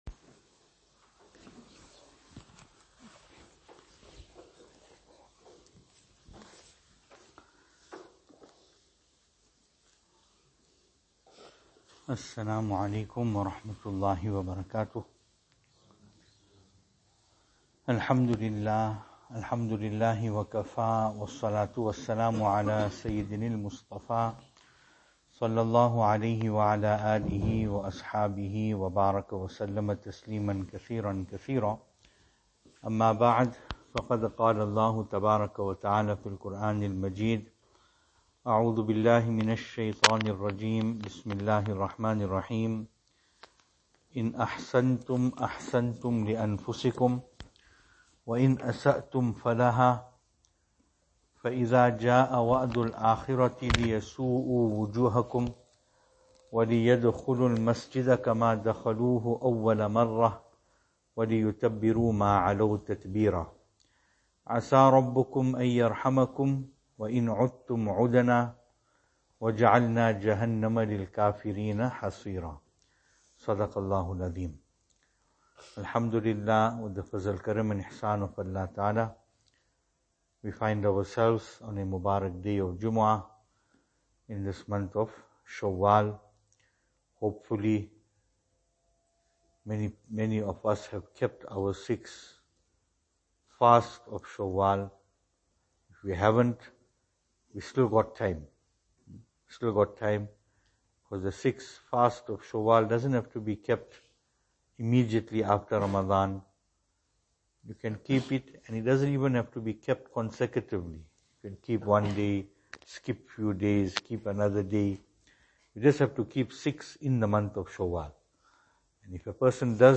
Venue: Albert Falls , Madressa Isha'atul Haq Service Type: Jumu'ah